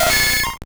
Cri de Nidorino dans Pokémon Or et Argent.